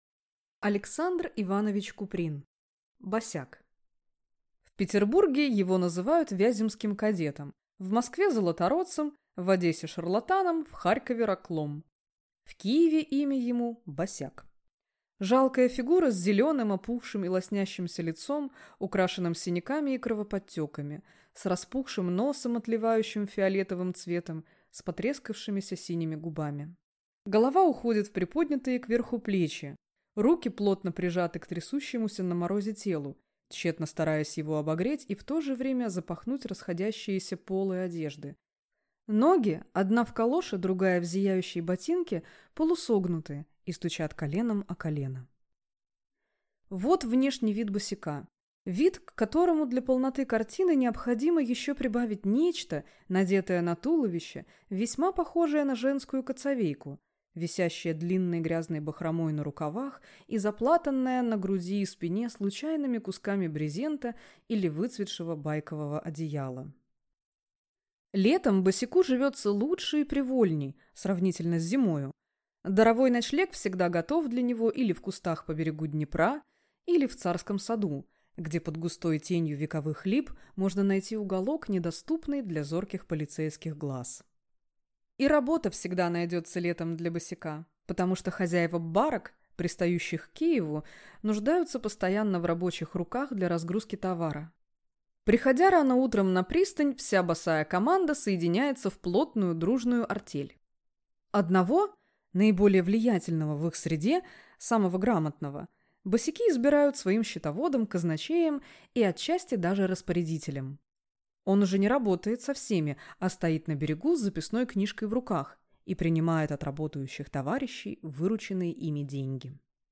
Аудиокнига Босяк | Библиотека аудиокниг